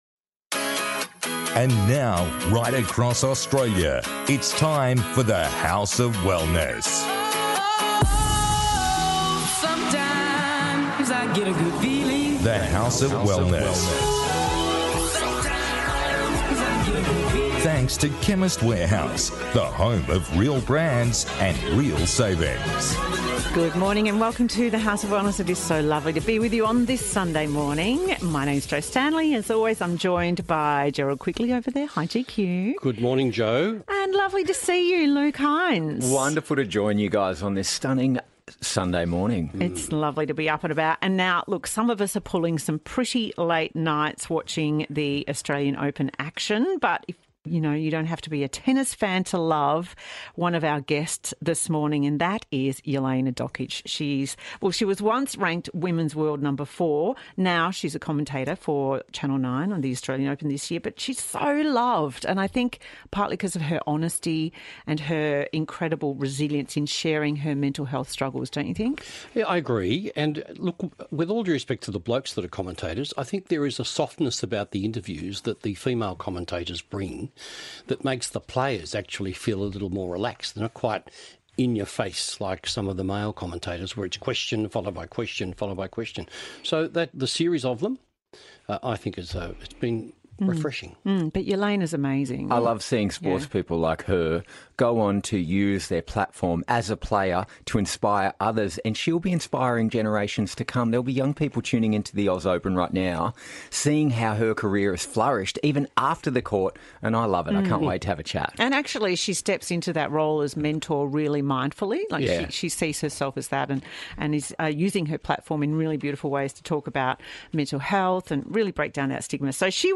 This week, the team chats to Jelena Dokic and gets expert tips on how to help kids transition back to school.